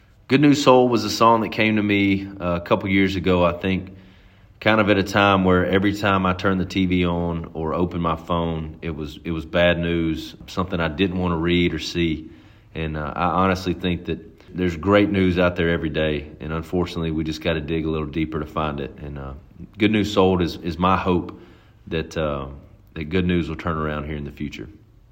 Jordan Davis talks about his song, "Good News Sold."